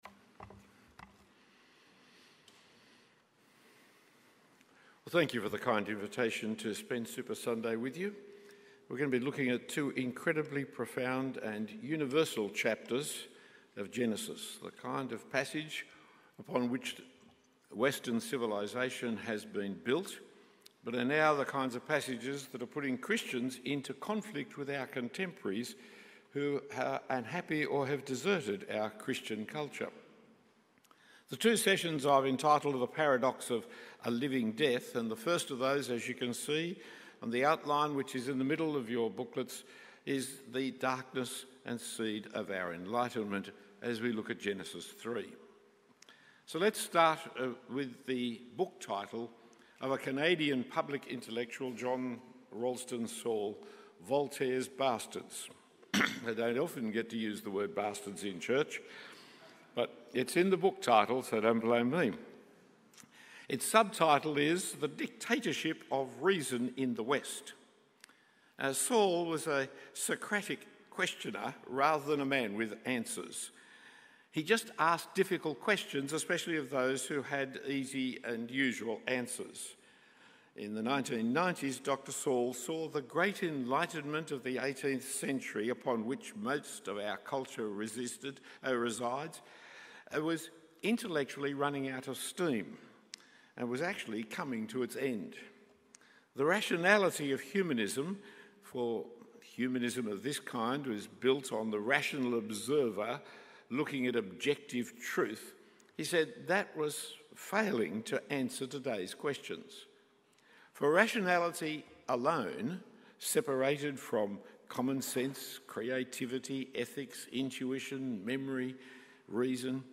Talk 1 of 2 given at a half-day conference, The Paradox of a Living Death, at St Thomas North Sydney.